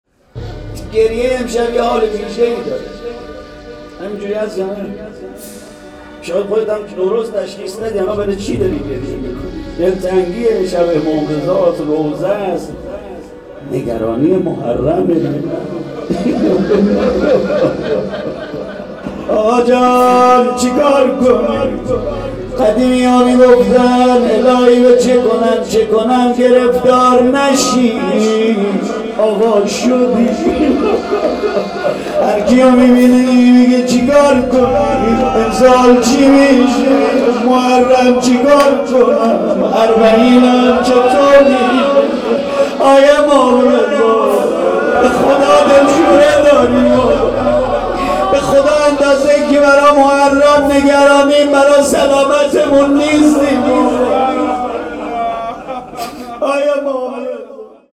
روضه جانسوز محرم